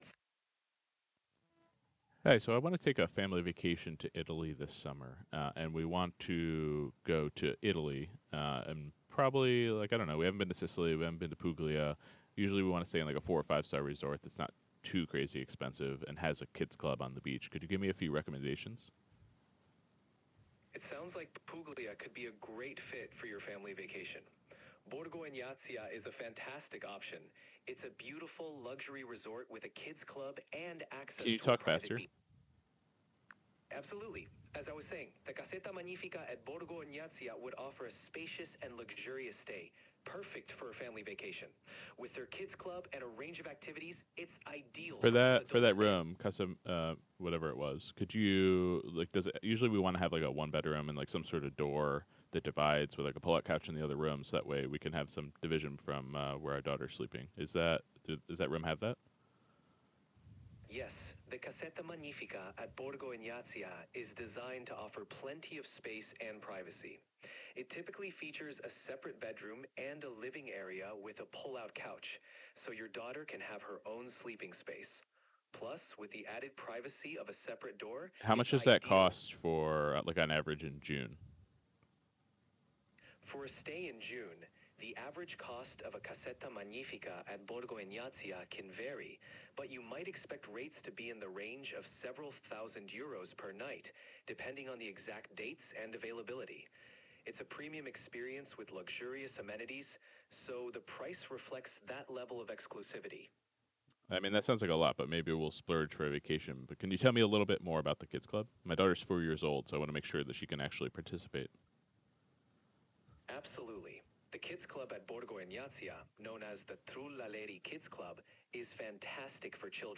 Here’s a sample of using ChatGPT as a travel agent for a family trip to Italy: